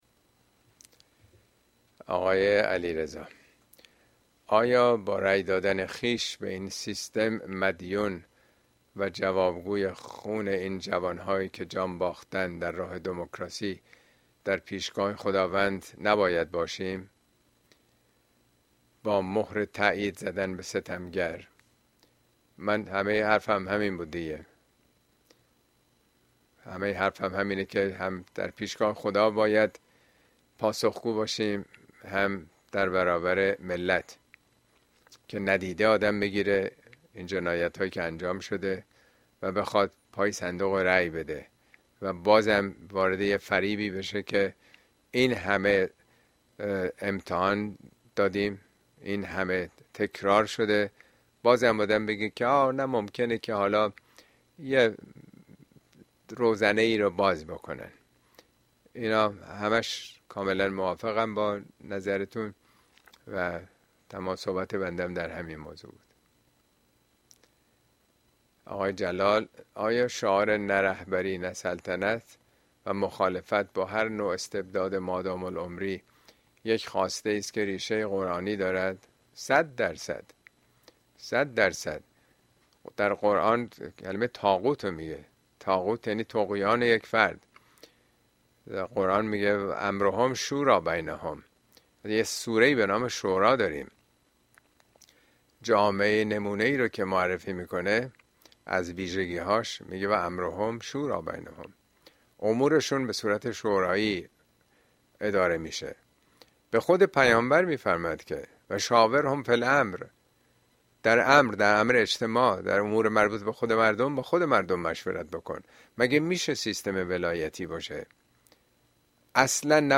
` موضوعات اجتماعى اسلامى !انتخاب بین بد و بدتر اين سخنرانى به تاريخ ۱۲ جون ۲۰۲۴ در كلاس آنلاين پخش شده است توصيه ميشود براىاستماع سخنرانى از گزينه STREAM استفاده كنيد.
If Player needed DOWNLOAD مدت سخنرانى: ۴۸ دقيقه STREAM به مدت ۲۷ دقيقه DOWNLOAD بخش پرسش و پاسخ جلسه